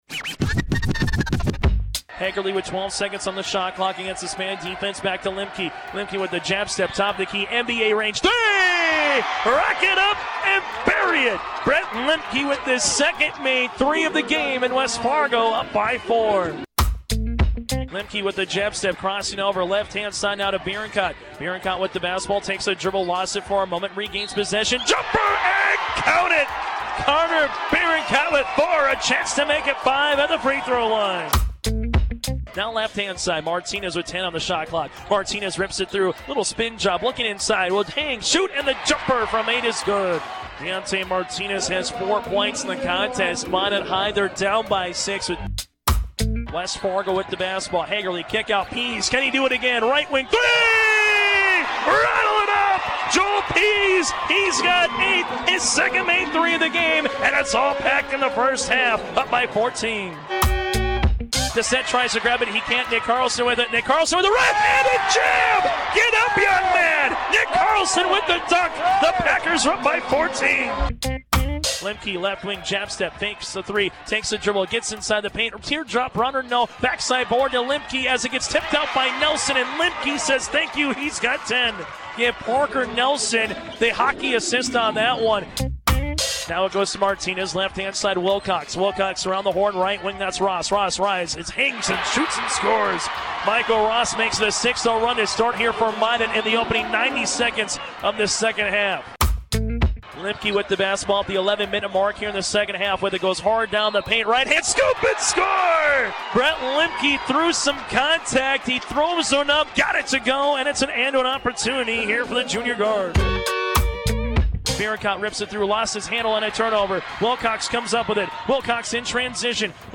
West Fargo won the Class A boys basketball state championship over Minot 65-60 from the Bismarck Events Center on March 14, 2021.
(Highlights)
west-fargo-vs-minot-highlight-package-1.mp3